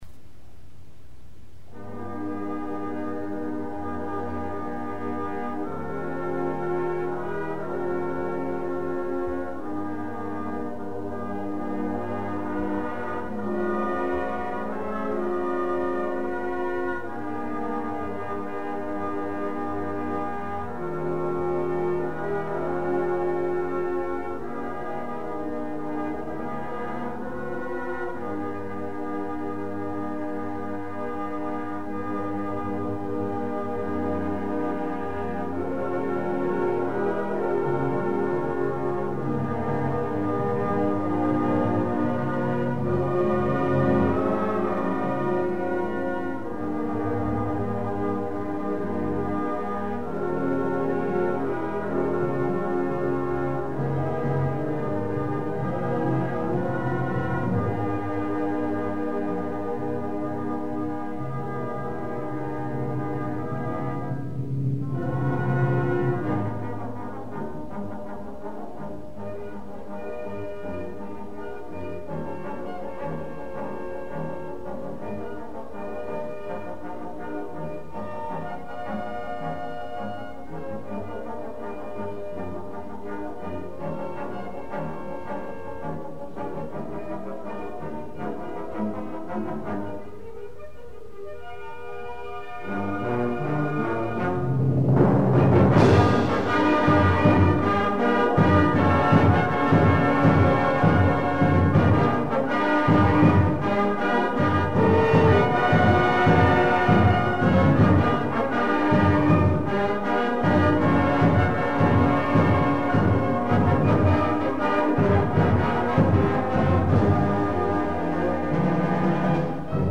ELJWB - Wind Ensemble - Concert Band March 17, 1978